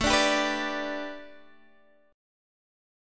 A9sus4 chord